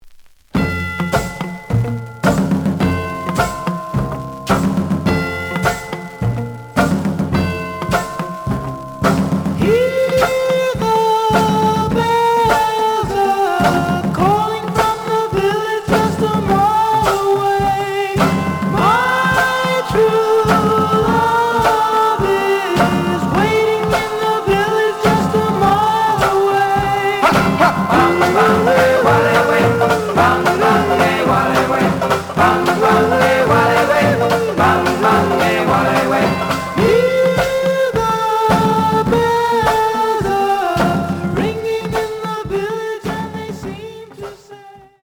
The audio sample is recorded from the actual item.
●Genre: Rock / Pop
Some noise on A side.